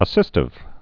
(ə-sĭstĭv)